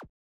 Shotgun Shoot.mp3